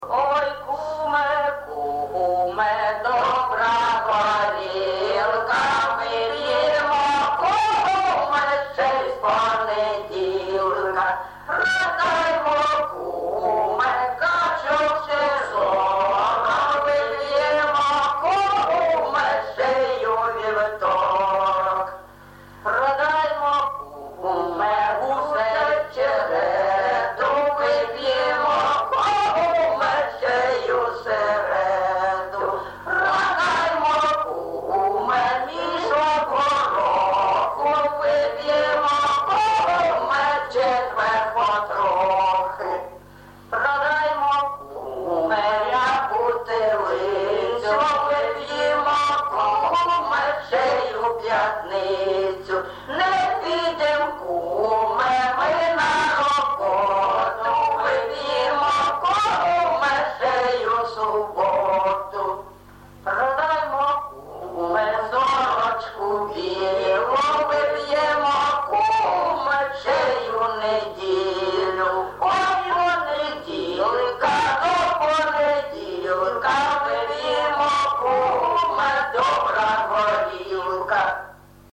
ЖанрЖартівливі, Пʼяницькі
Місце записум. Єнакієве, Горлівський район, Донецька обл., Україна, Слобожанщина